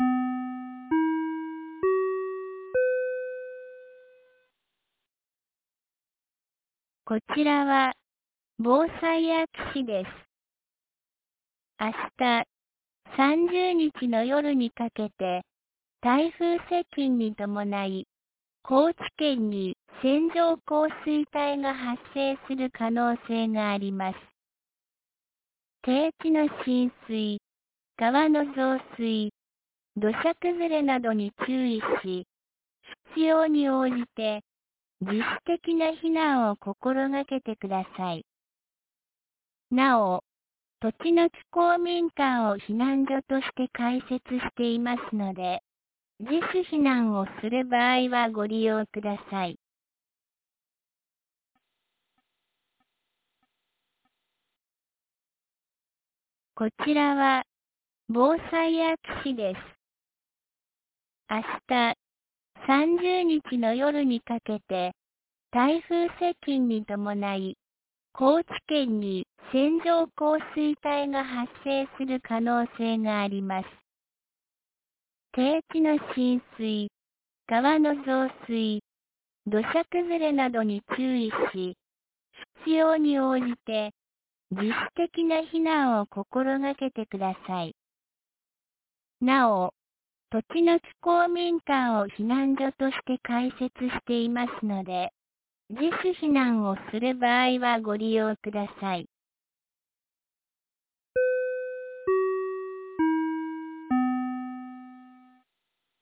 2024年08月29日 15時46分に、安芸市より栃ノ木へ放送がありました。